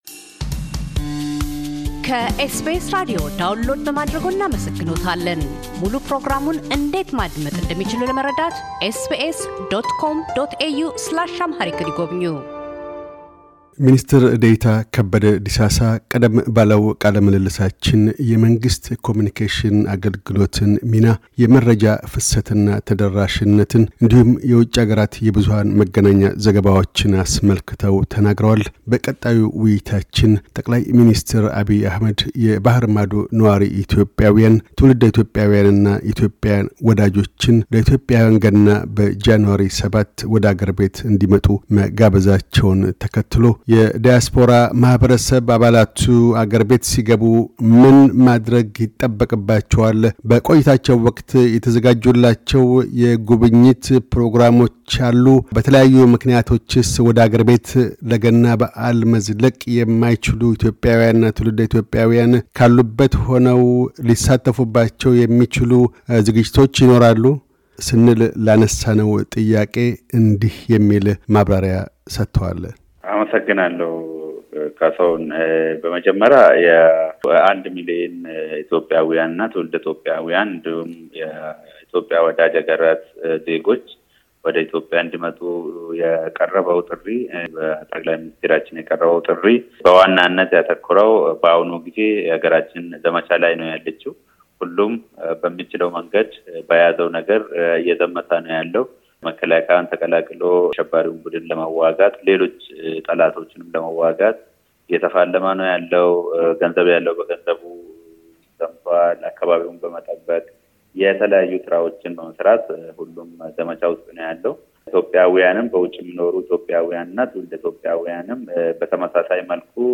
ከበደ ዲሳሳ - የመንግሥት ኮሙዩኒኬሽን አገልግሎት ሚኒስትር ደኤታ፤ በዕቅድ ተይዞ ስላለው የአንድ ሚሊየን የባሕር ማዶ ነዋሪ ኢትዮጵያውያን፣ ትውልደ-ኢትዮጵያውያንና የኢትዮጵያ ወዳጆች የአገር ቤት ጉዞ መሰናዶዎችና ፋይዳዎችን ያመላክታሉ።